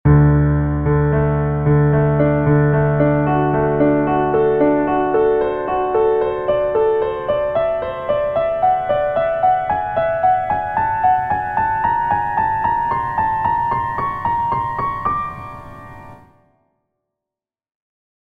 反之，泛音列在12平均律上的取整听上去才是相对歪的。